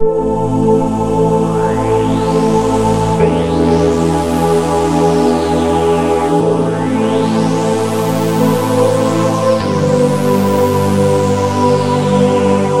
描述：氛围，从外部空间的凉爽垫环... :)
Tag: 75 bpm Ambient Loops Pad Loops 1.08 MB wav Key : A